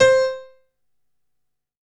55ay-pno04-c4.wav